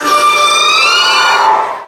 Cri de Momartik dans Pokémon X et Y.